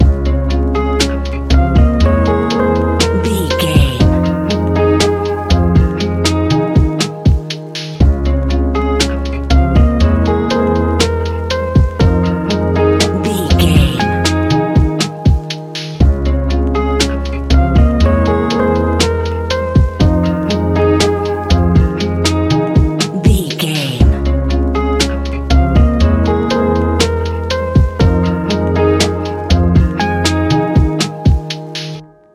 Ionian/Major
C♭
chilled
laid back
Lounge
sparse
new age
chilled electronica
ambient
atmospheric
morphing